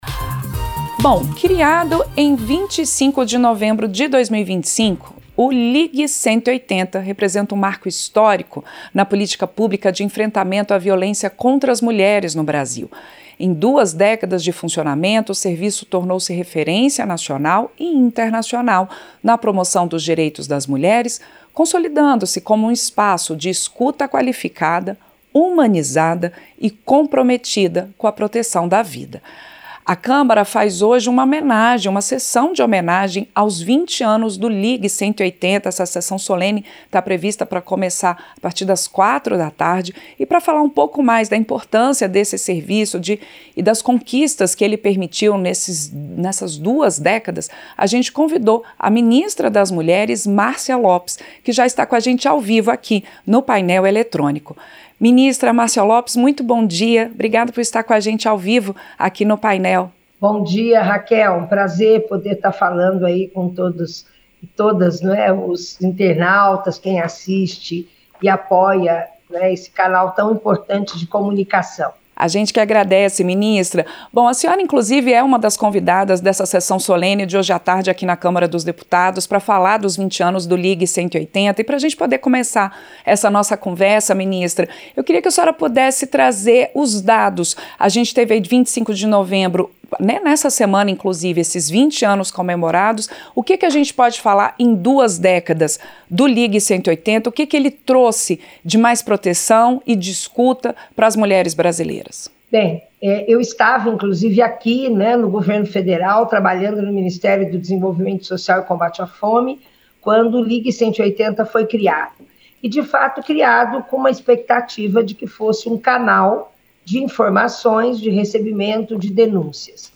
Entrevista - Ministra da Mulheres, Márcia Lopes